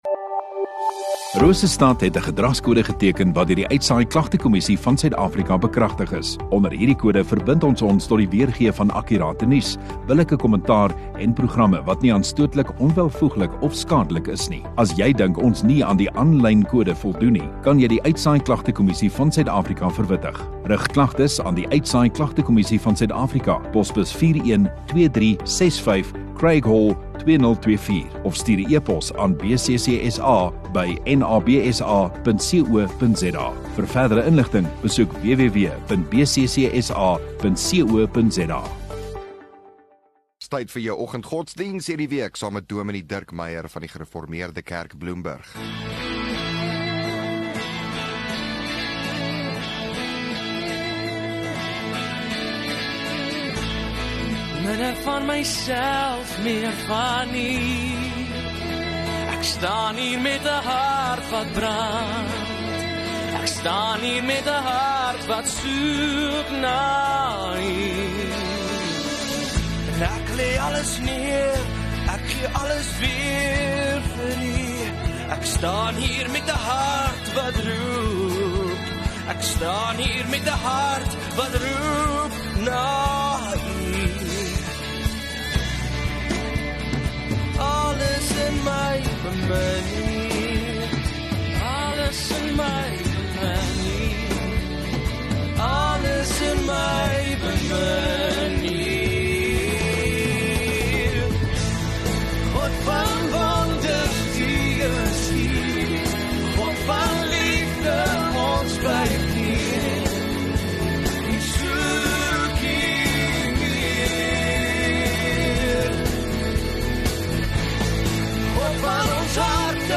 6 Aug Woensdag Oggenddiens